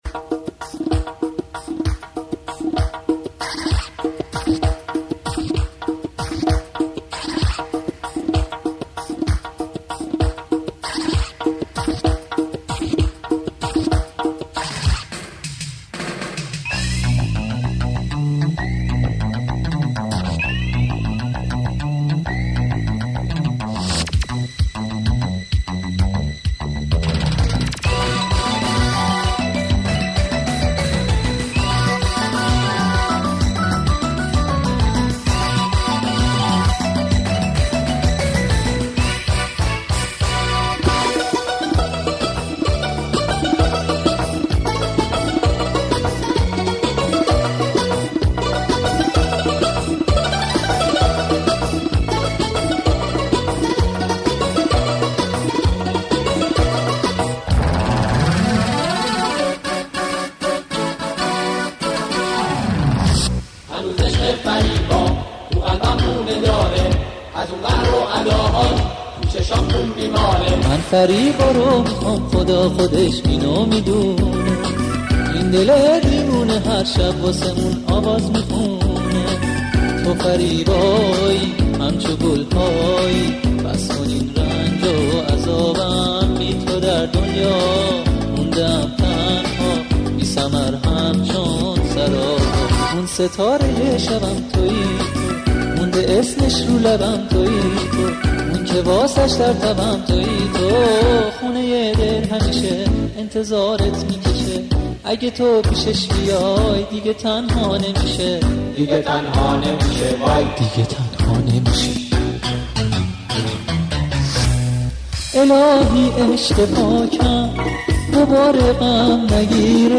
آهنگ قدیمی
آهنگ شاد قدیمی